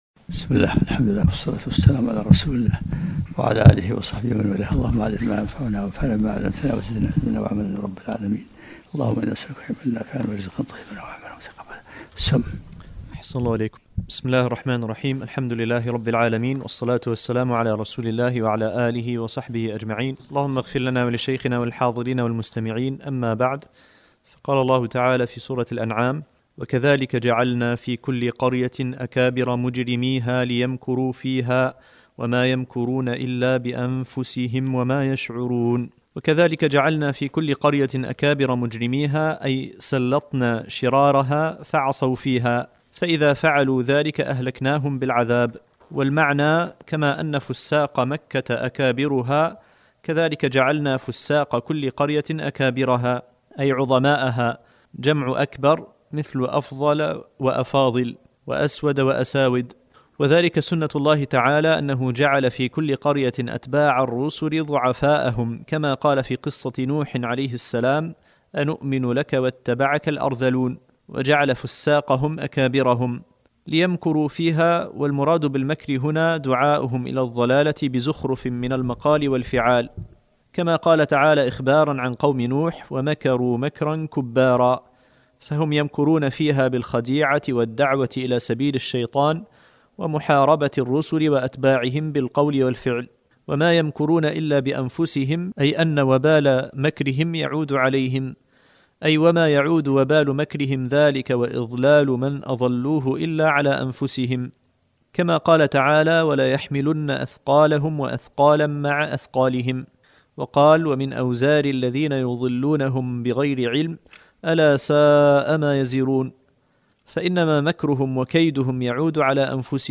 الدرس الخامس و العشرون من سورة الانعام